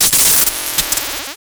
stunarmor.wav